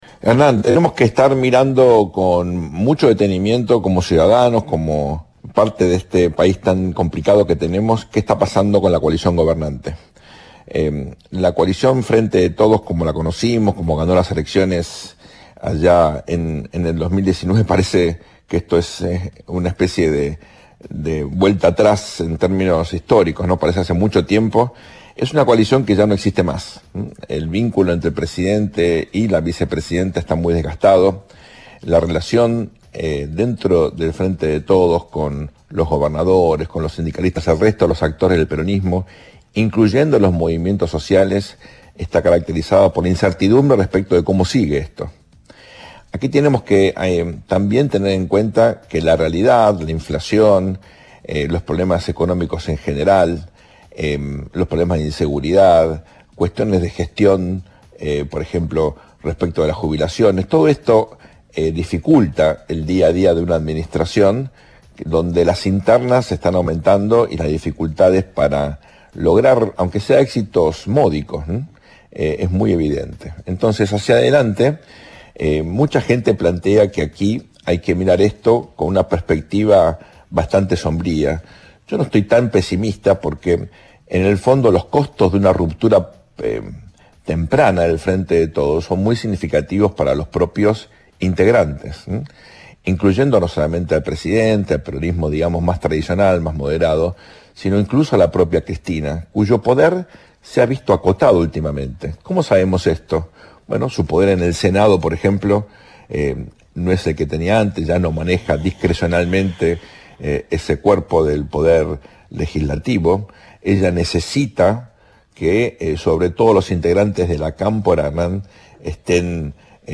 En su primer análisis para Radioinforme 3 Rosario, el analista Sergio Berensztein consideró que si bien entre el presidente Alberto Fernández y la vicepresidenta Cristina Fernández hay un “vinculo disfuncional”, resulta difícil pensar en una ruptura por los costos para el gobierno.